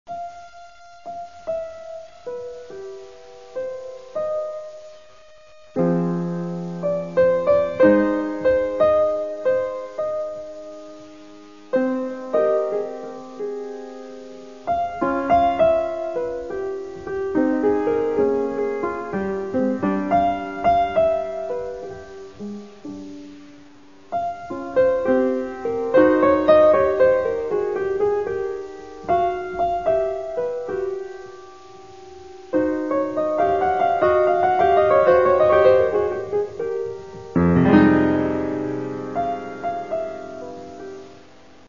Болгарська фортепіанна метроритміка
Твір 78. 16 метроритмічних п'єс для фортепіано